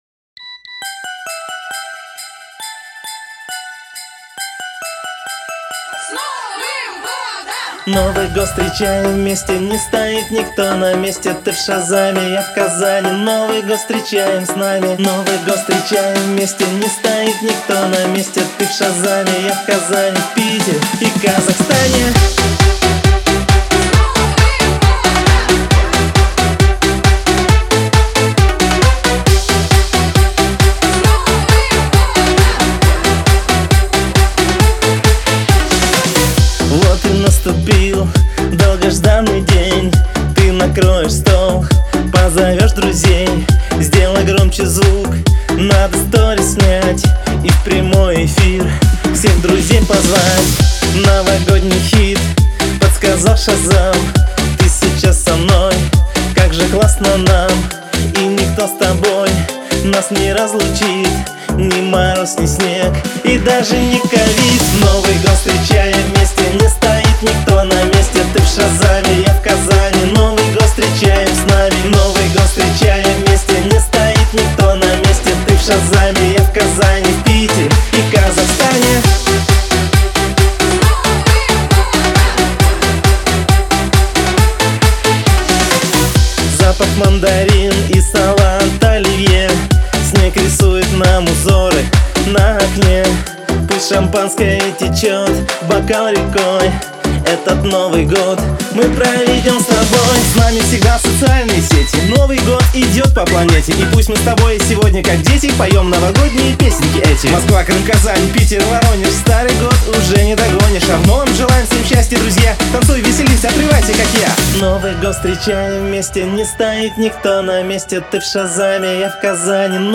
это энергичная и зажигательная песня в жанре поп-рок